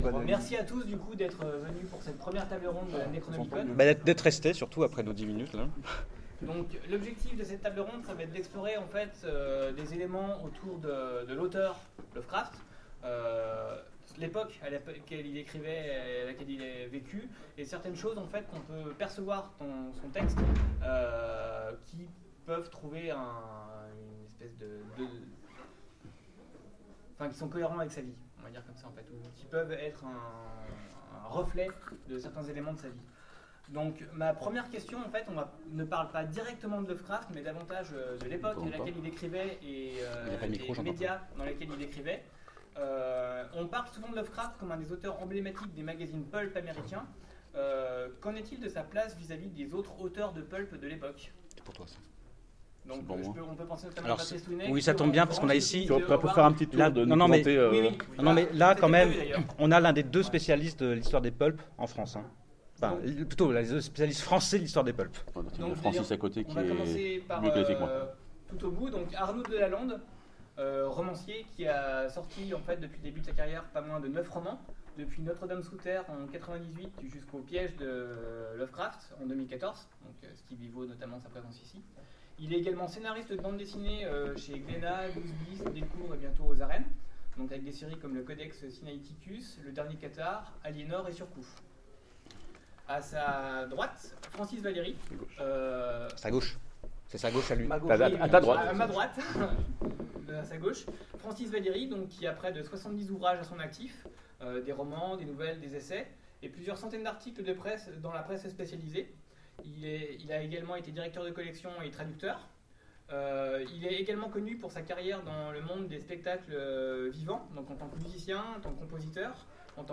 Necronomicon 2015 : Conférence HP Lovecraft, l'homme